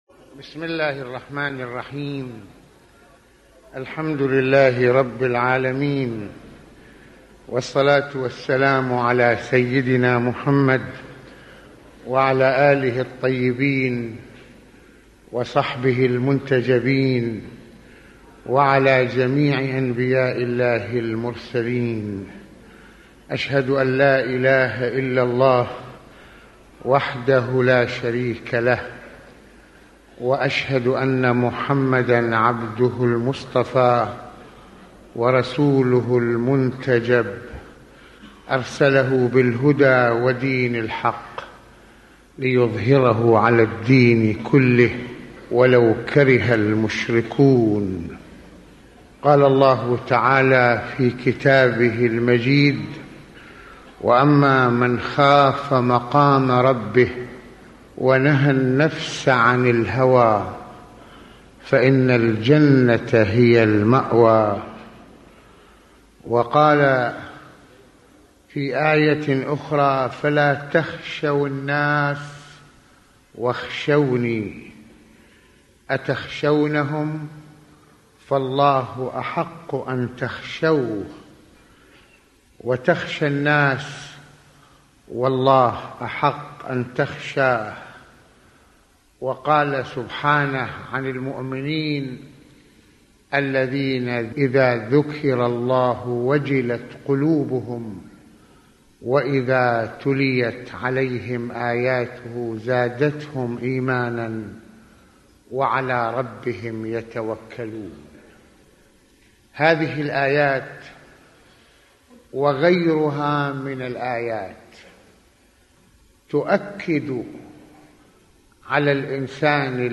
- يتحدث العلامة المرجع السيد محمد حسين فضل الله (رض) في هذه المحاضرة عن أهمية خوف الإنسان من ربّه انطلاقاً من وعيه لعظمته المطلقة، ويتناول سماحته (رض) وصية من وصايا لقمان الحكيم لإبنه حول المسألة كذلك قصة من القصص التي تشير إلى ضرورة خشية الله تعالى في السر والعلانية..